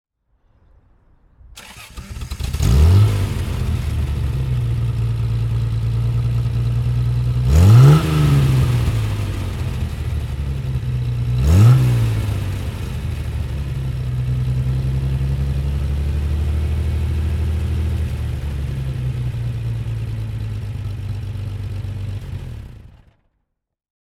Fiat Barchetta 103 Sport 1200 S (1957) - Starten und Leerlauf